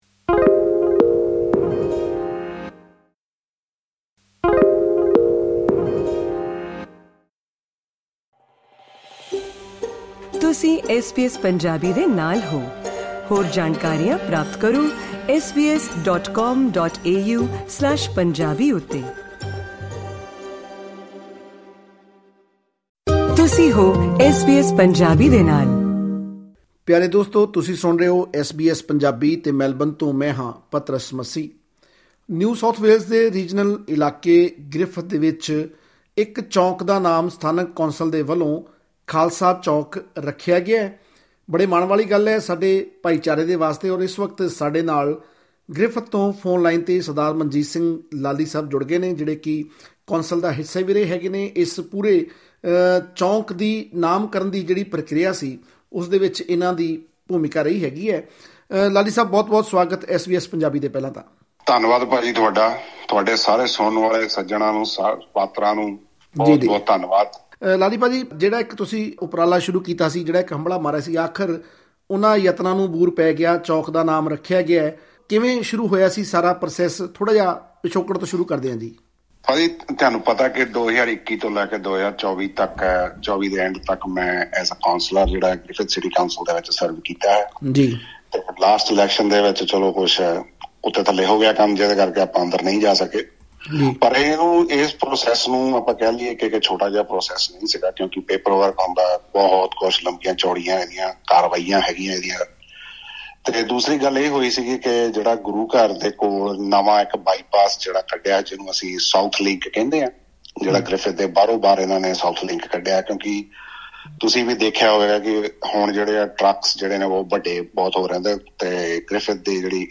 Speaking to SBS Punjabi, former Griffith councillor Manjit Singh Lally said the journey to reach this milestone was not easy.